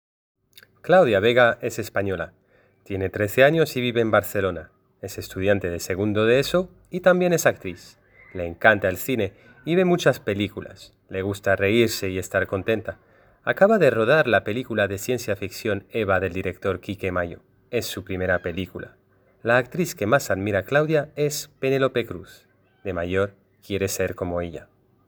Escucha la lectura del profesor: pincha en  (clique sur) el azul (primer texto) o pincha en el verde (segundo texto)